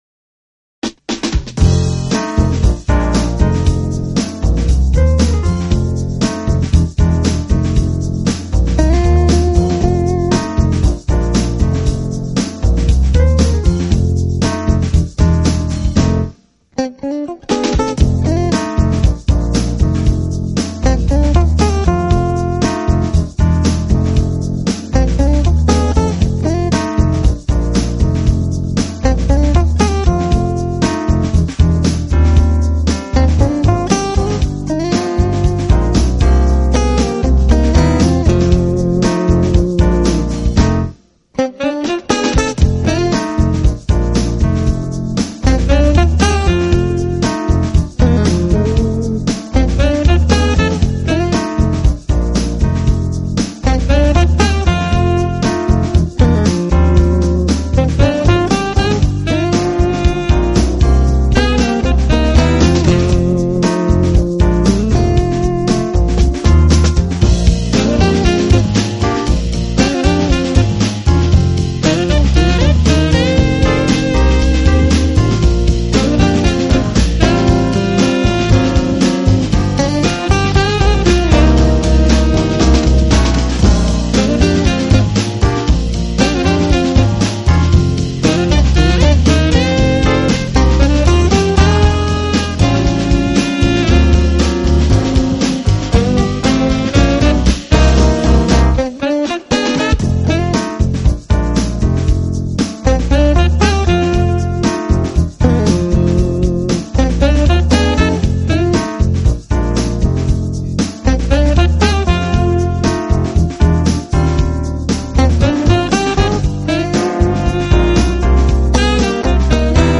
【爵士吉他】
整体来看，这是一张现代爵士的专辑唱片，
吉他和SAX都表现的非常
清新明快的节奏可以使人一天都精神十足，更显对人生积极